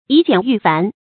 以简驭繁 yǐ jiǎn yù fán
以简驭繁发音